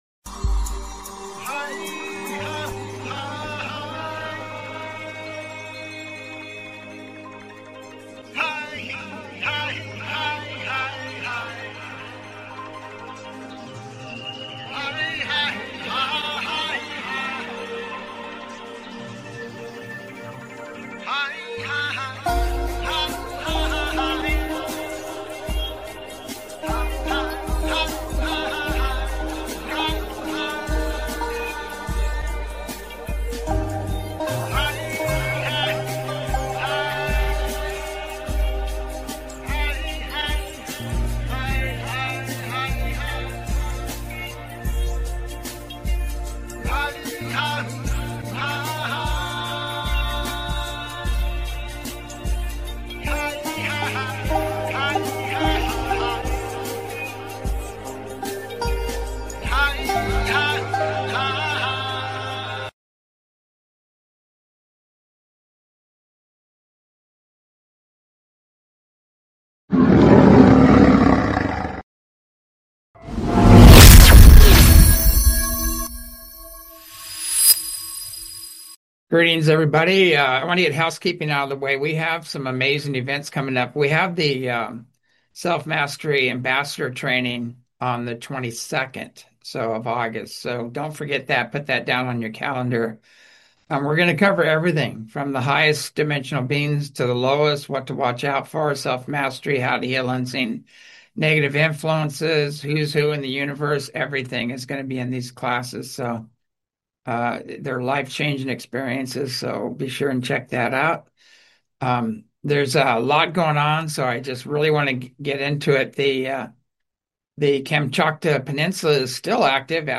Talk Show Episode, Audio Podcast, As You Wish Talk Radio and 31 Atlas Oumuamua, Epstein, Earth Changes, Fall of the Deep State on , show guests , about 31 Atlas Oumuamua,Epstein,Earth Changes,Fall of the Deep State, categorized as Earth & Space,News,Paranormal,UFOs,Philosophy,Politics & Government,Science,Spiritual,Theory & Conspiracy